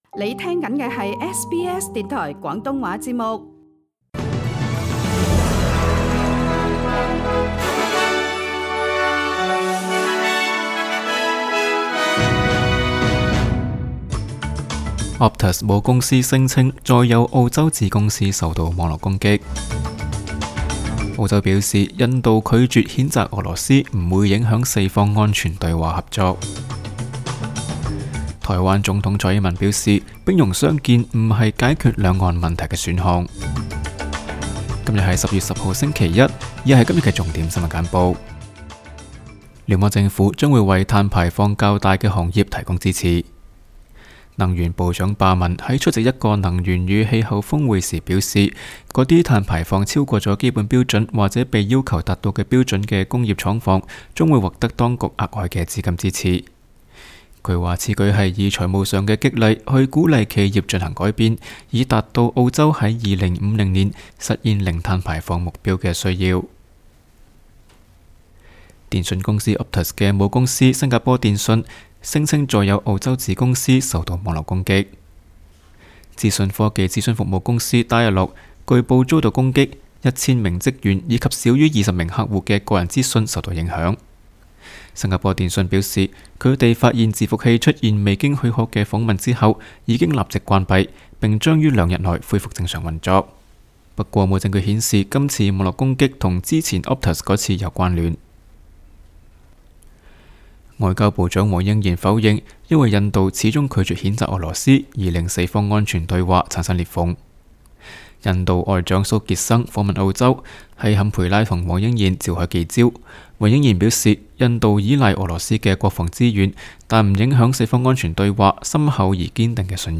SBS 新聞簡報（10月10日）
SBS 廣東話節目新聞簡報 Source: SBS / SBS Cantonese